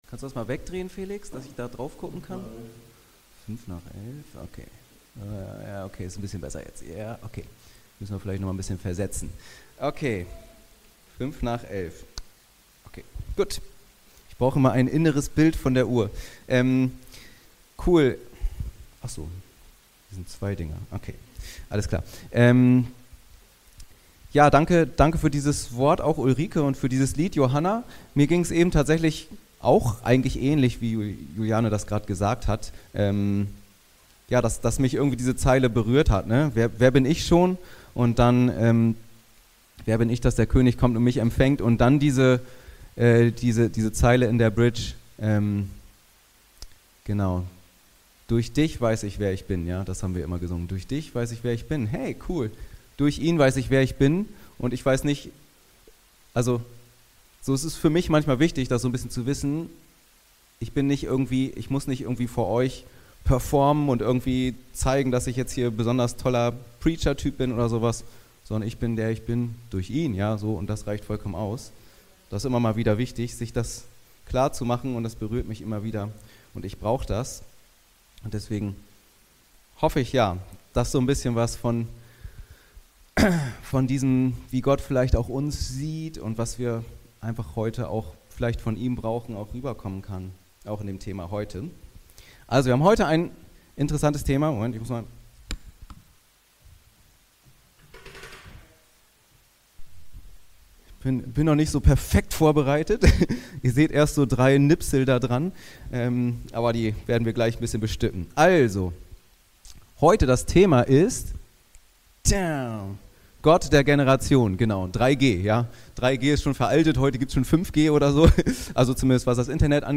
Gott der Generation ~ Anskar-Kirche Hamburg- Predigten Podcast